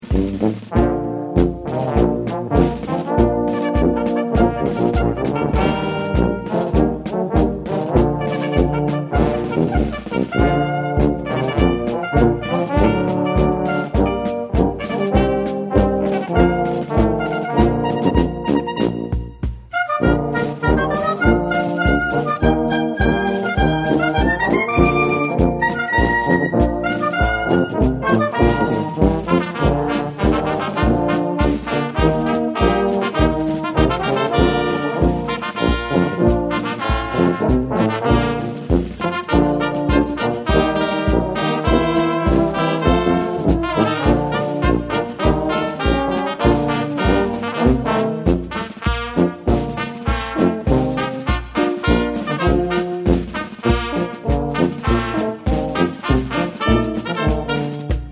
Accueil Marches et galops John Brown's Body John Brown's Body Traditionnel Écoutez John Brown's Body de Traditionnel interpreté par la Fanfare Octave Callot Téléchargez le morceau ← En r'venant d'la r'vue ↑ Marches et galops Jules et Jim →